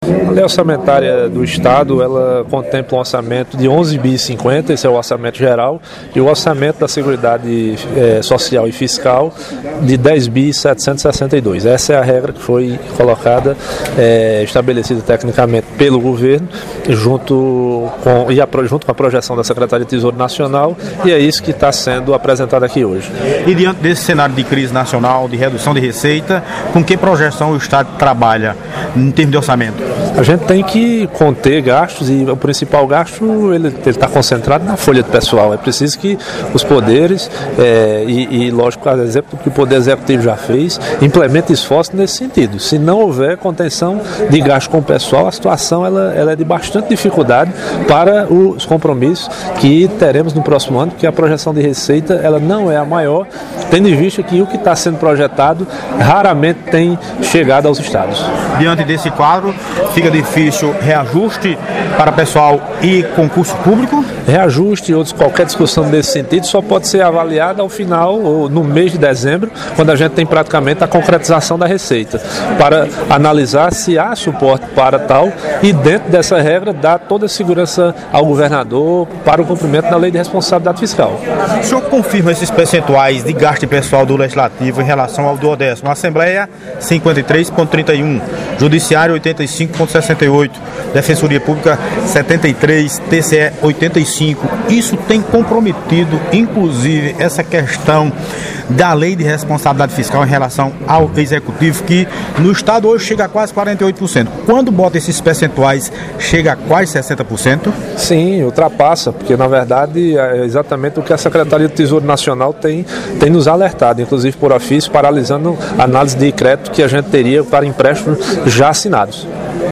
Assembleia debate Lei Orçamentária Anual 2018 com a presença do Secretário de Planejamento Waldson Souza
Num debate que durou mais de quatro horas, o secretário de Estado do Planejamento, Orçamento e Gestão, Waldson Souza, participou, nesta quinta-feira (26), de audiência pública na Assembleia Legislativa da Paraíba para debater com os parlamentares, representantes da sociedade civil e entidades a Proposta de Lei Orçamentária Anual (LOA) de 2018. De acordo com a LOA, o orçamento total do Estado previsto para 2018 é de R$ 11.050 bilhões.